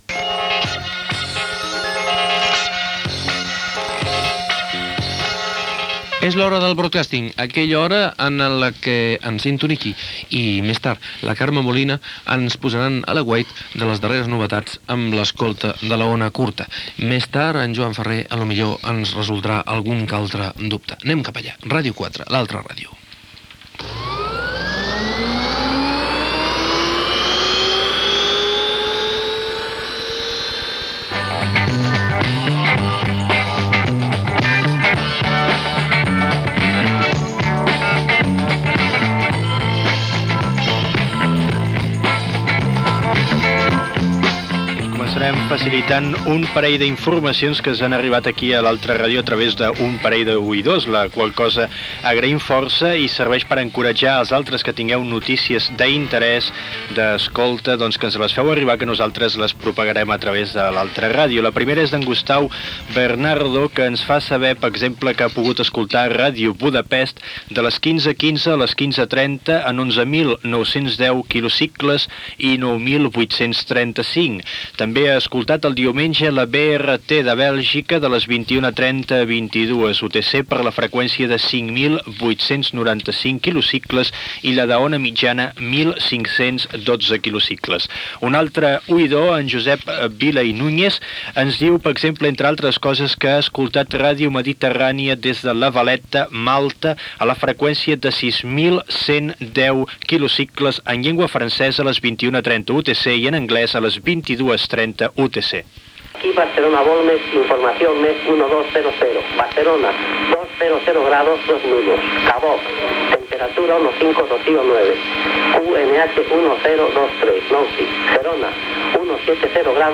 Informació aportada pels oïdors i reportatge sobre les estacions meteorològiques per a l'aviació (volmets).
Divulgació